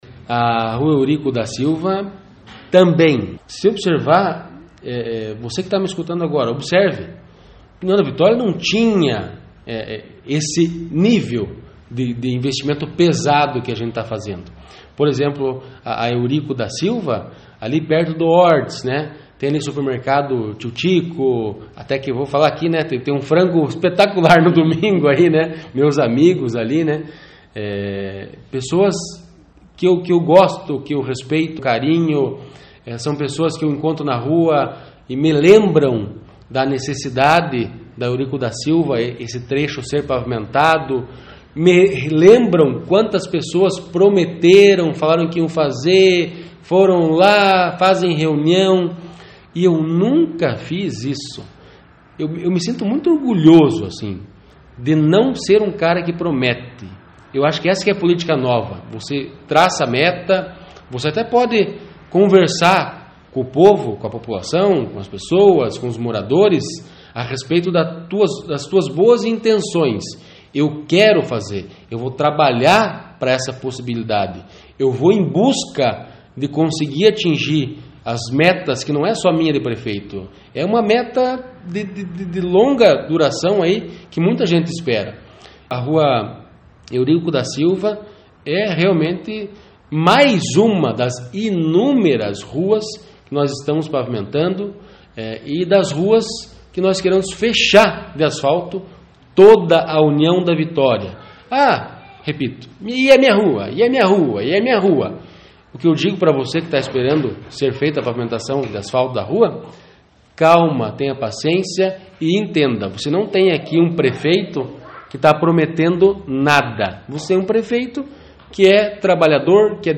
PREFEITO-SANTIN-ROVEDA-FALA-DA-PAVIMENTAÇÃO-DA-RUA-EURICO-DA-SILVA-NO-HORST-WALDRAFF.mp3